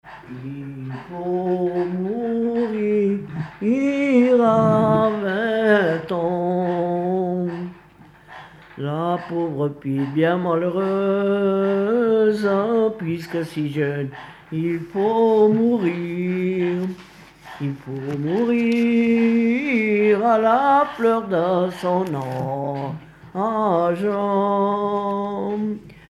Mieussy
Pièce musicale inédite